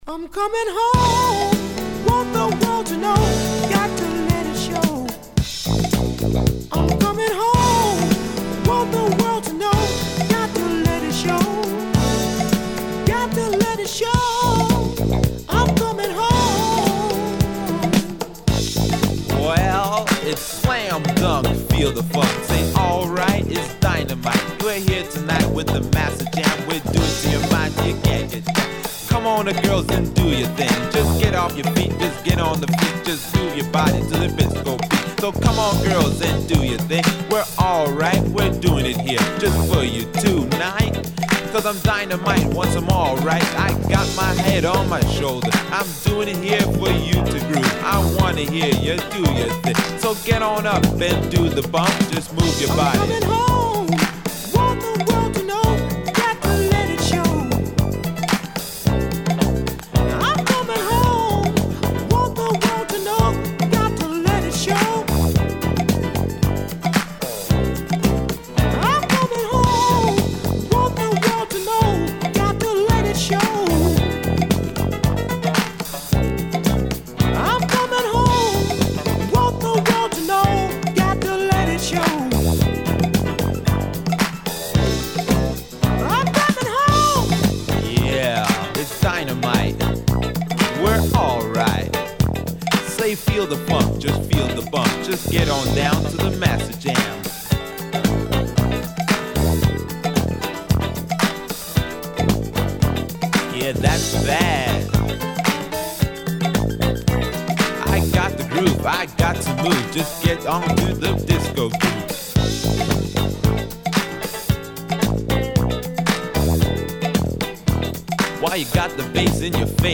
Old school rap